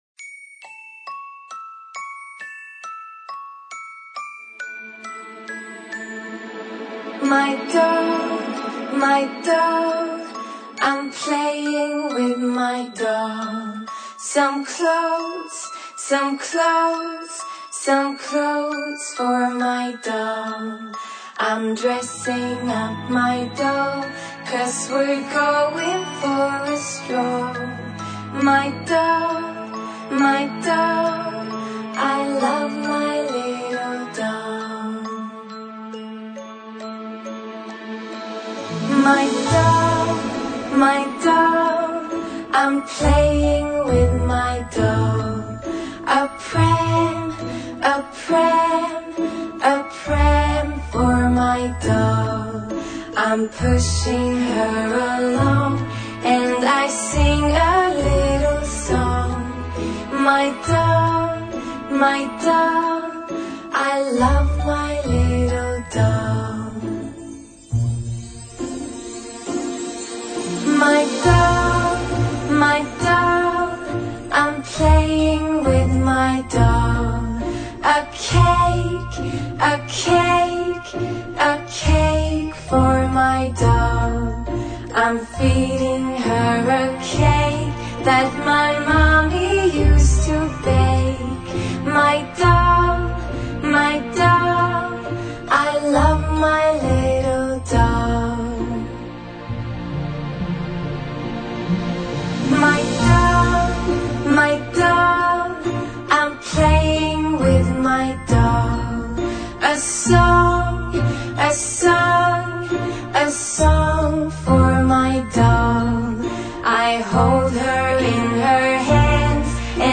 描述：轻柔的摇篮曲。